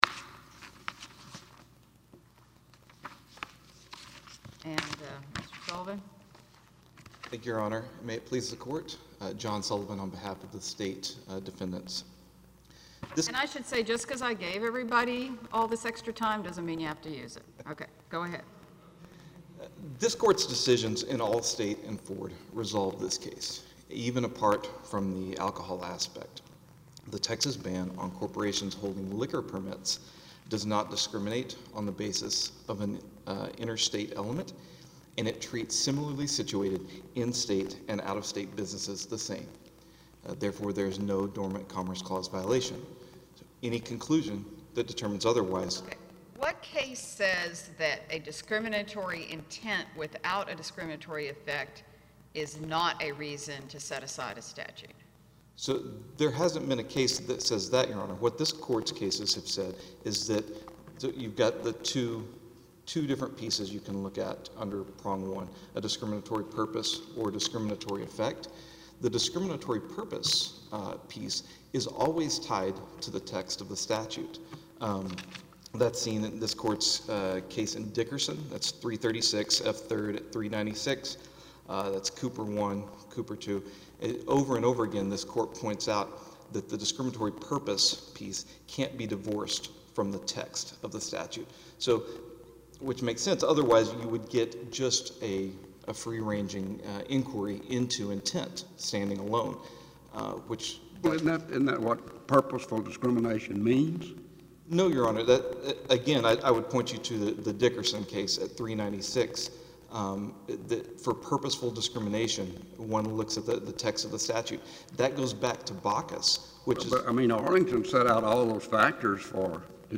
The Fifth Circuit held oral argument on April 29th on Walmart’s challenge to Texas liquor laws impacting the number of Walmart stores that can sell liquor. The hearing was in New Orleans and held before Judges Davis, Haynes and Graves.
The hearing seems to show one judge somewhat supportive of Walmart, one somewhat of the state and one judge who did not say much at all.